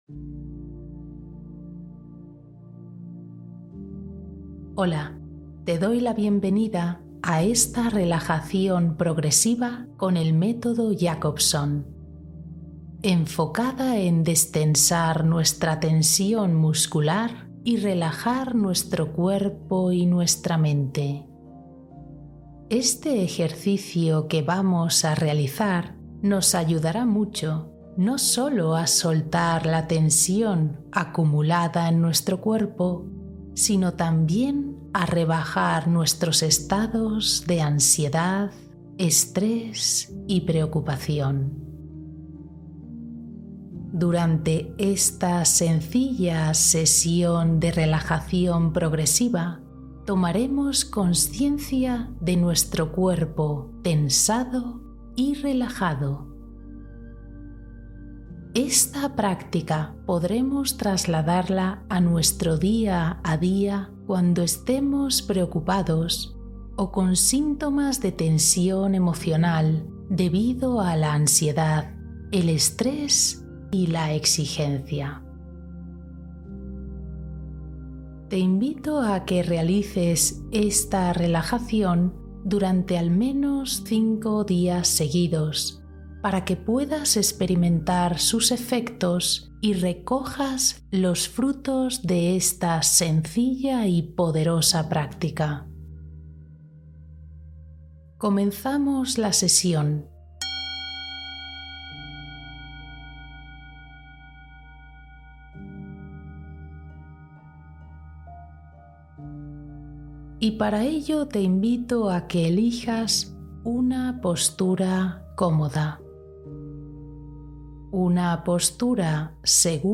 Respiración guiada para una relajación total y consciente